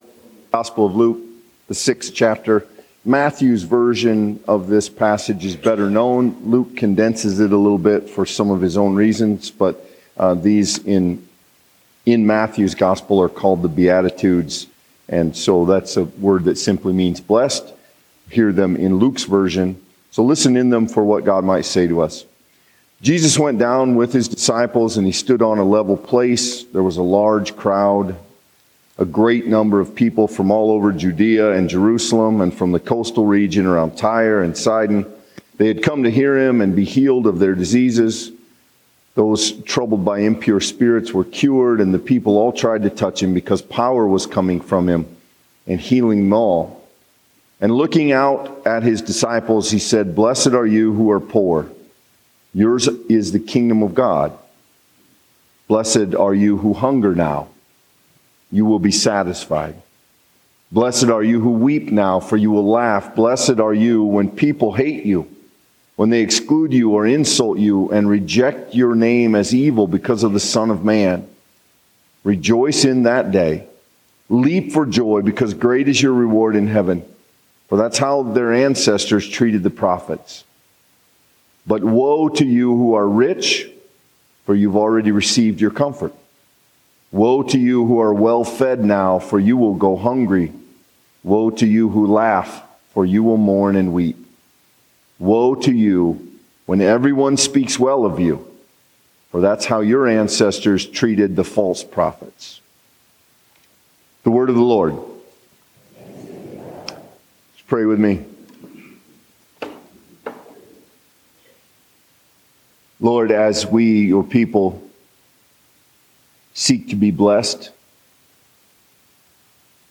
The 8:50 worship service at First Presbyterian Church in Spirit Lake.